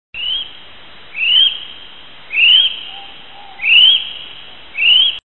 malij-kivi-apteryx-owenii.mp3